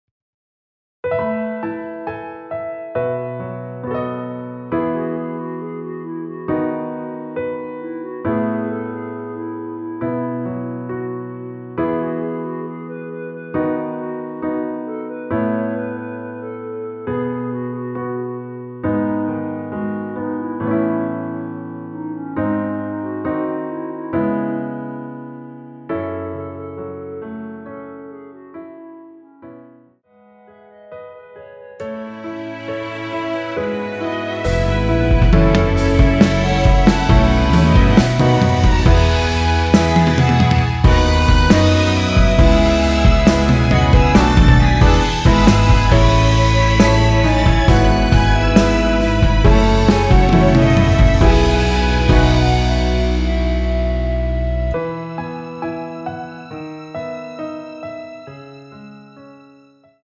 원키에서(+1)올린 멜로디 포함된 MR입니다.(미리듣기 확인)
◈ 곡명 옆 (-1)은 반음 내림, (+1)은 반음 올림 입니다.
앞부분30초, 뒷부분30초씩 편집해서 올려 드리고 있습니다.